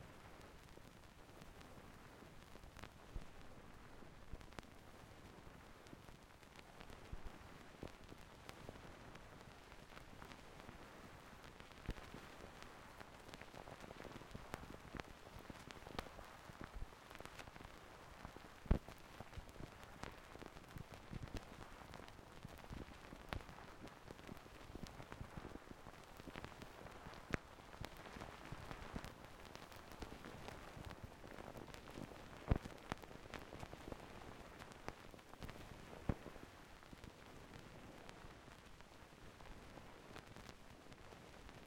phono_pops.ogg